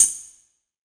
WTAMBOURIN19.wav